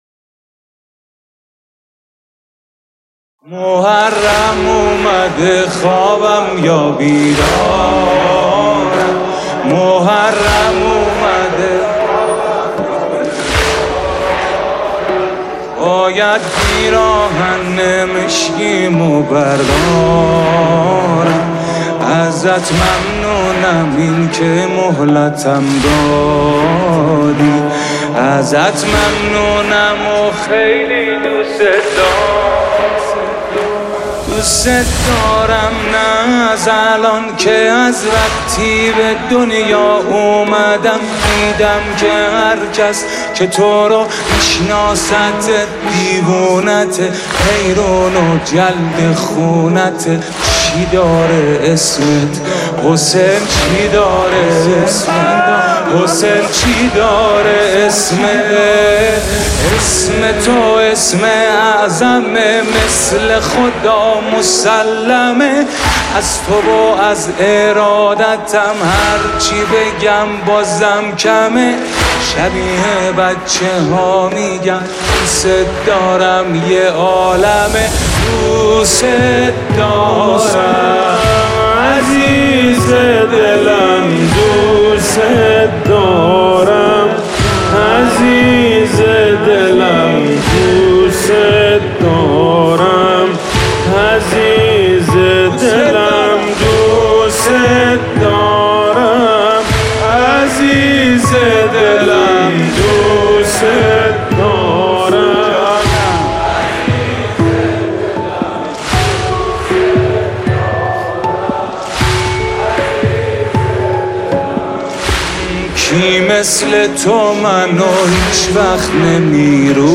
مداحی دلنشین